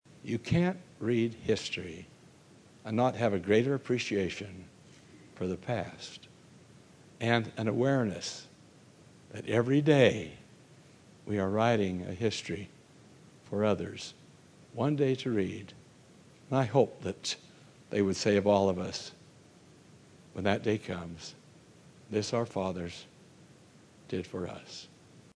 At the time capsule ceremony for the library, Church president Thomas S. Monson, speaking of the importance of preserving history,